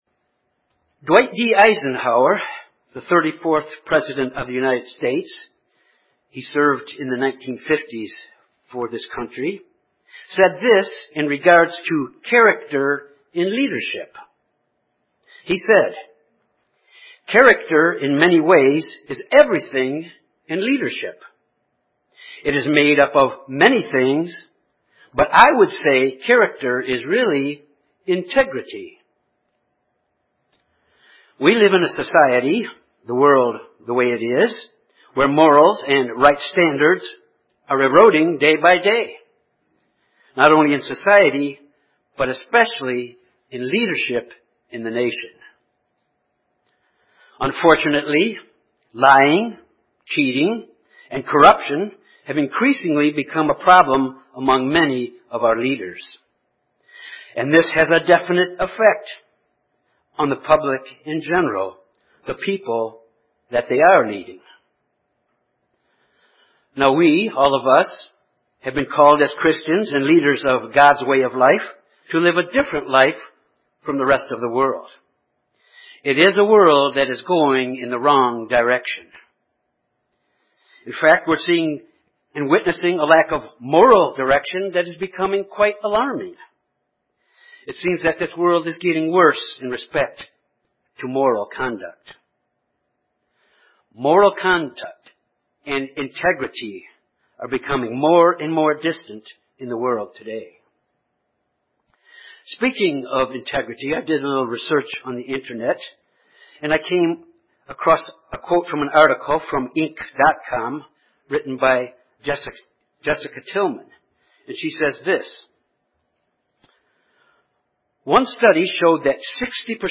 Given in Jonesboro, AR Little Rock, AR Memphis, TN